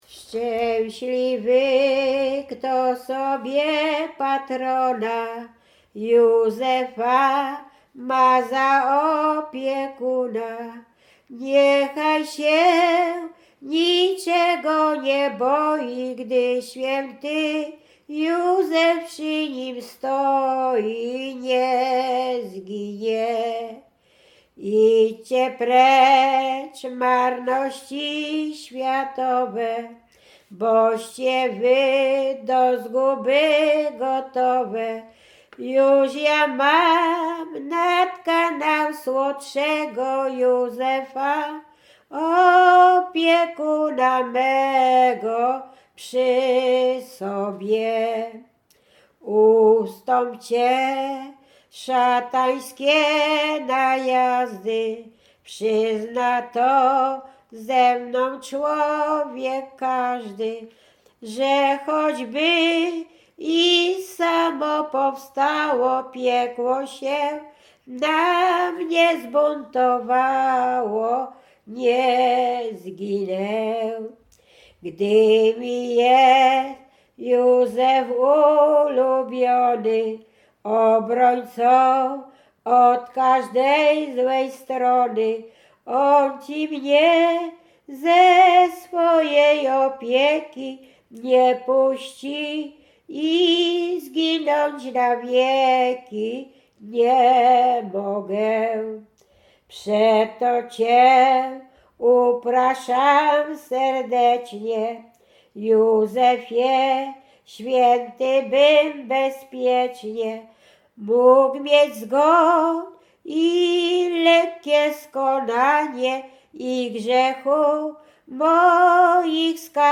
Łęczyckie
województwo łódzkie, powiat łódzki, gmina Zgierz, wieś Jasionka
Pogrzebowa
pogrzebowe nabożne katolickie do grobu o świętych